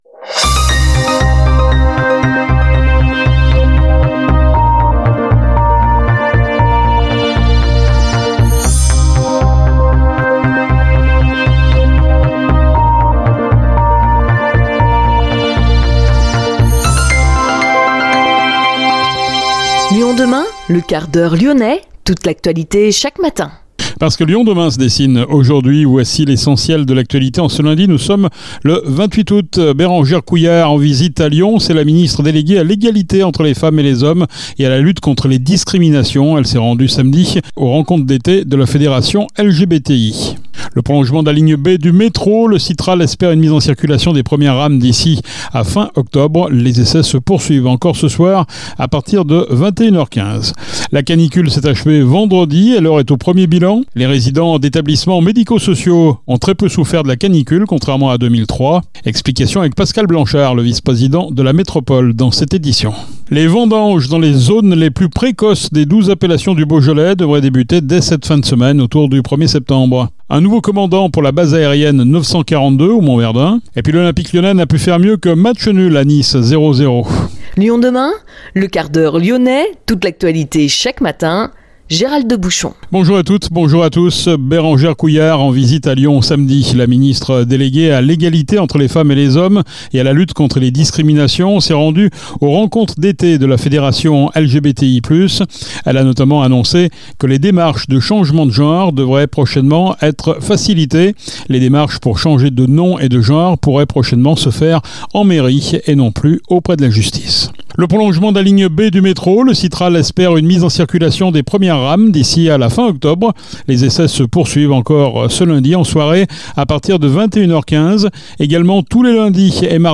Pascal Blanchard, vice-président de la Métropole, est notre invité